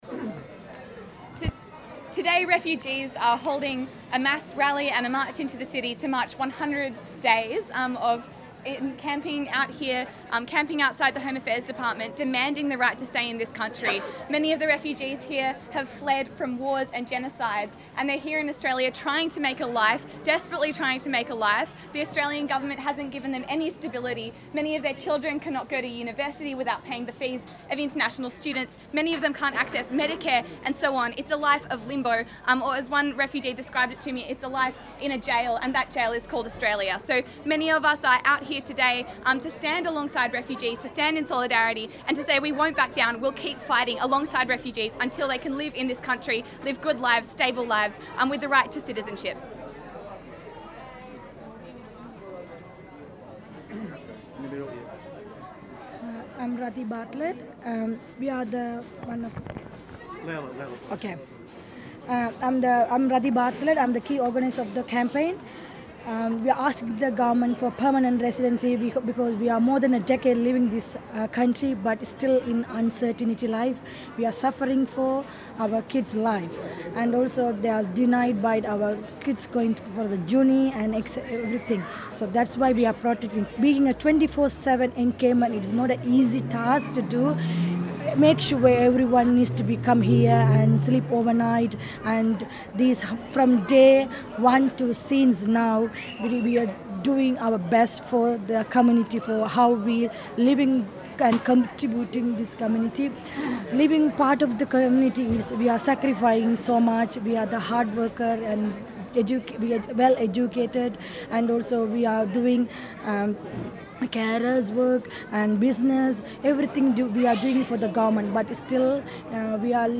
here II Refugees who have been encamped at the Home Affairs office in Docklands held a media conference to celebrate the 110 days of resistance and to reiterate their call for permanent visas.
100 days of resistance celebration press conference.mp3